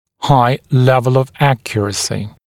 [haɪ ‘levl əv ‘ækjərəsɪ][хай ‘лэвл ов ‘экйэрэси]высокий уровень точности